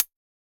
UHH_ElectroHatC_Hit-13.wav